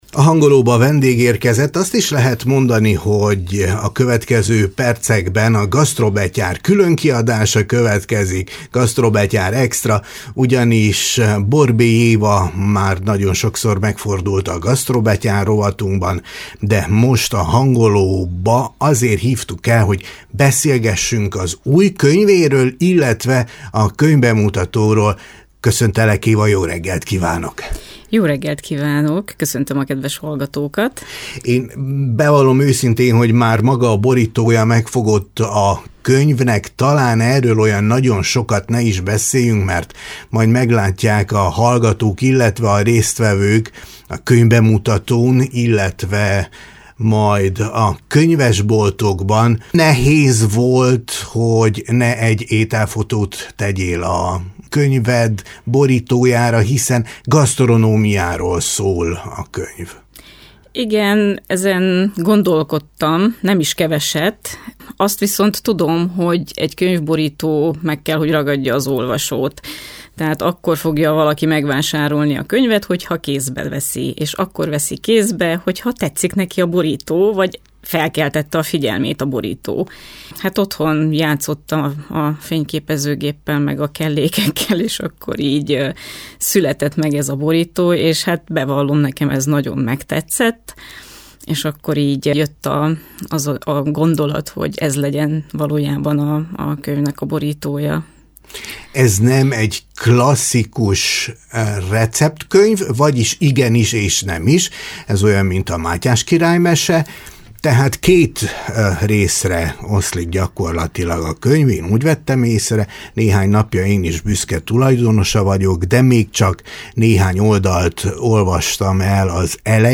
Az alábbiakban a könyvről és a bemutatóról készült beszélgetést hallgathatják.